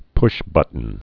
(pshbŭtn)